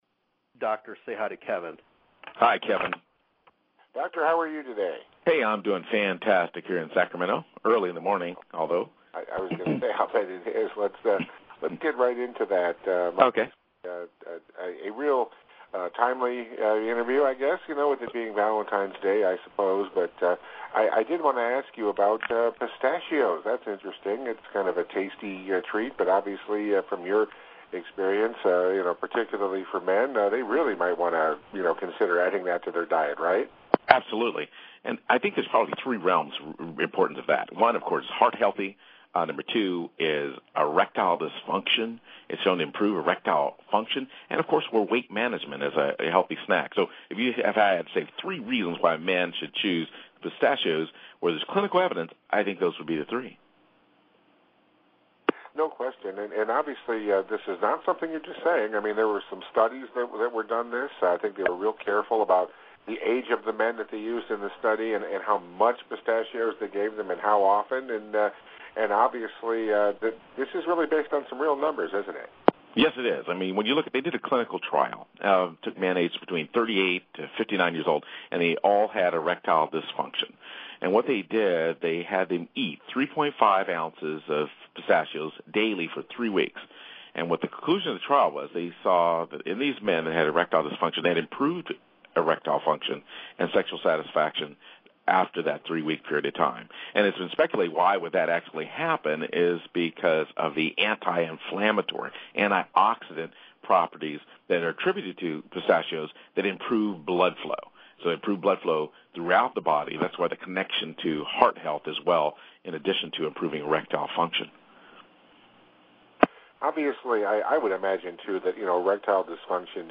Radio interviews: